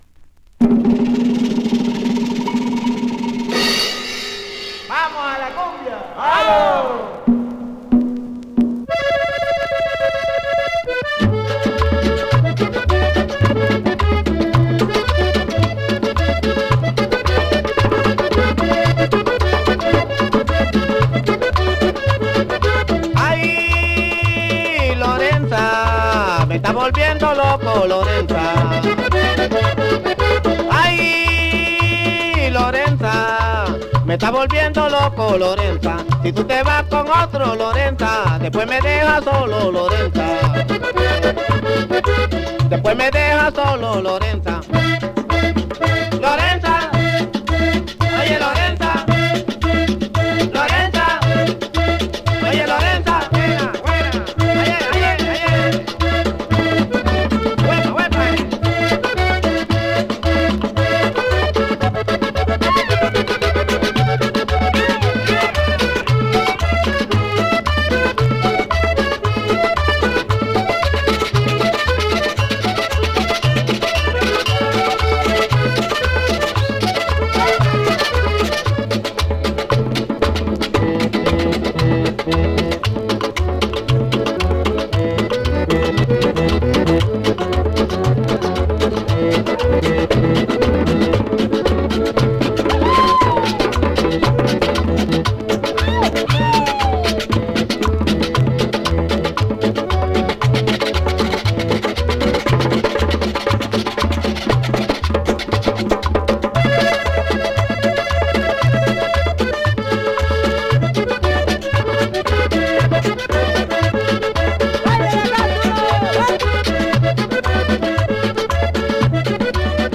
Published February 10, 2012 Cumbia Leave a Comment